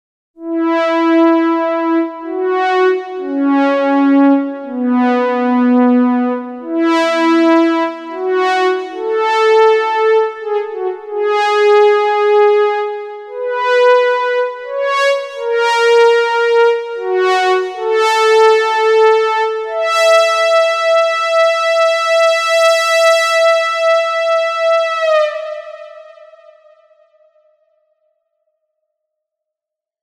Delay och reverb pålagt i Cubase för att få stereobild och lite rymd på ljuden.
Både velocity och aftertouch styr flera parametrar.
M6R-BladeHorn.mp3